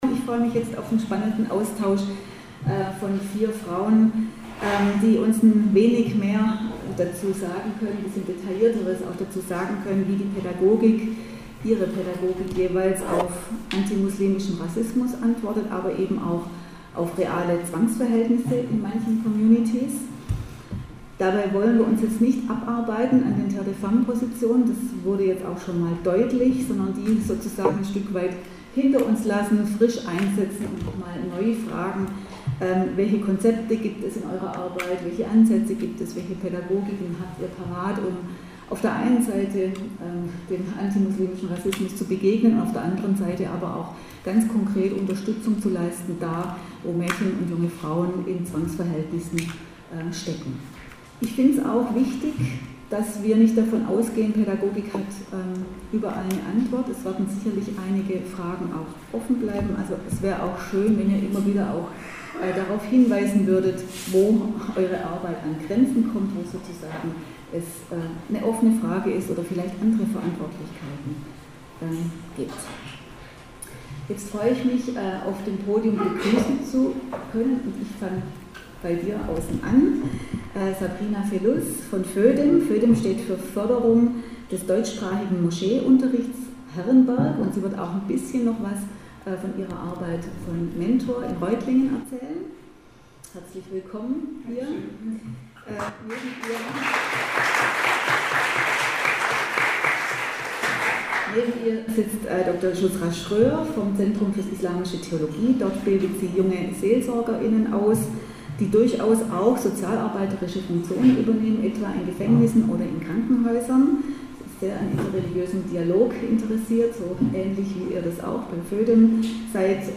Am 10. Juli trafen sich vier Pädagoginnen in der alten Aula in Tübingen, um über das von der Frauenrechtsorganisation „Terre des Femmes“ formulierte Kopftuchverbot für minderjährige Mädchen in Bildungsinstitutionen zu diskutieren.